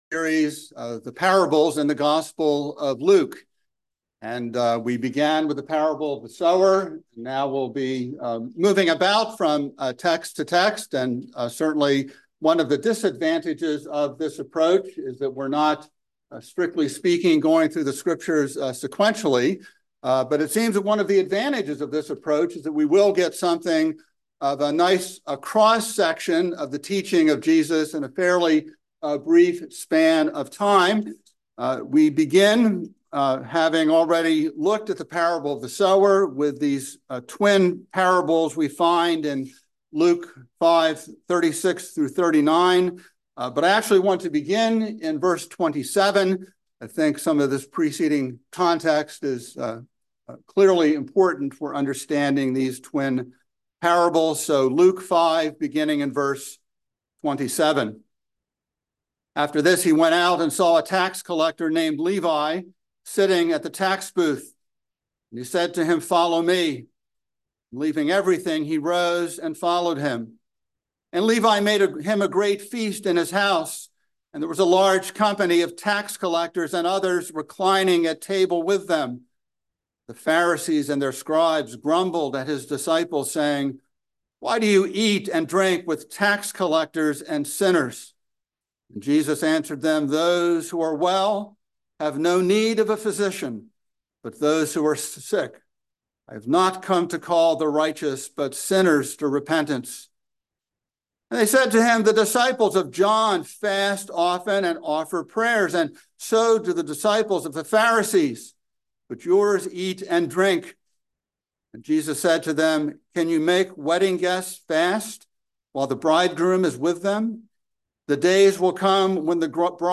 by Trinity Presbyterian Church | Feb 8, 2024 | Sermon